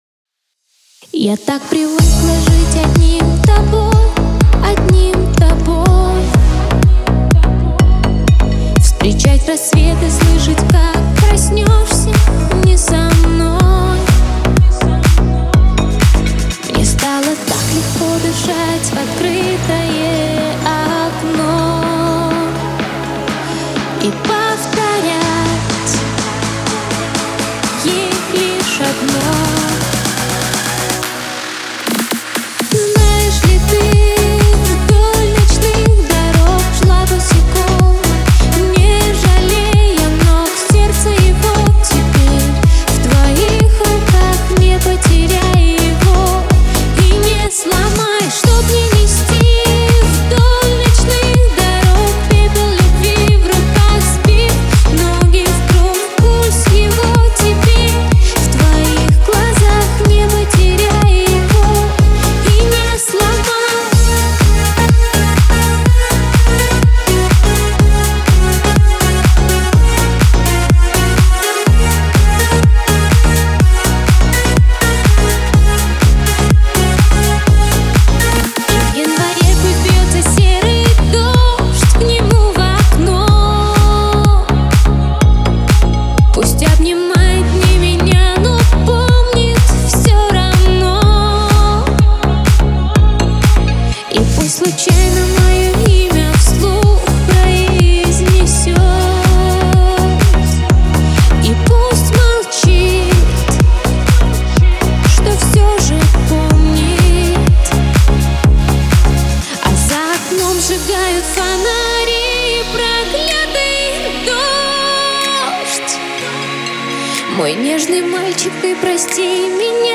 динамичными ритмами, создавая атмосферу легкости и романтики